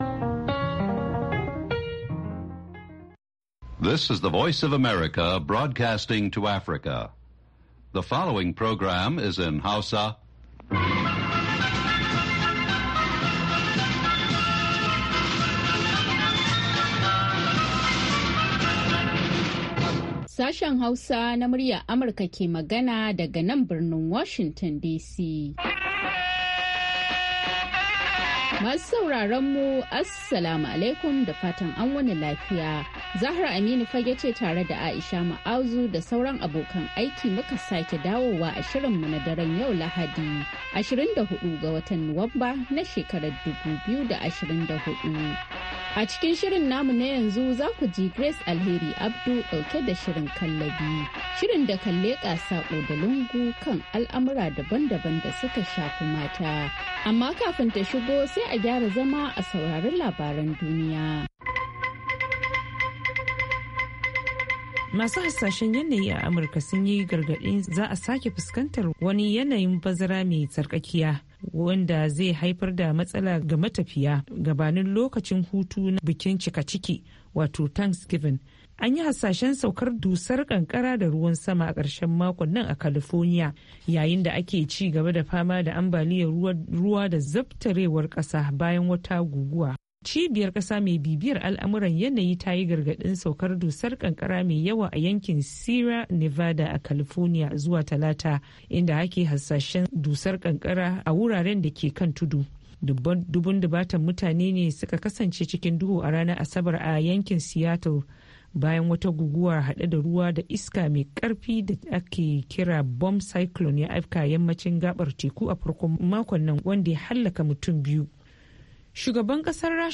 Kallabi wani sabon shiri ne kacokan kan mata, daga mata, a bakin mata, wanda Sashen Hausa na Muryar Amurka ya kirkiro don maida hankali ga baki daya akan harakokin mata, musamman a kasashenmu na Afrika. Shirin na duba rawar mata da kalubalensu ne a fannoni daban-daban na rayuwa.